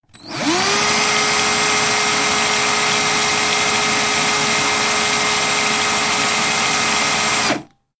Все треки четкие и реалистичные.
дрель в работе